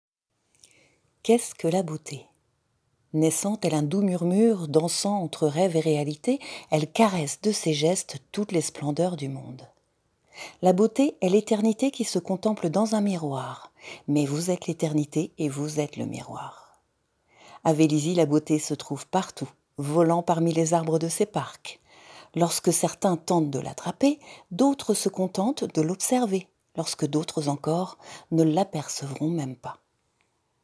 Voix off
Bandes-son
Voix douce
40 - 60 ans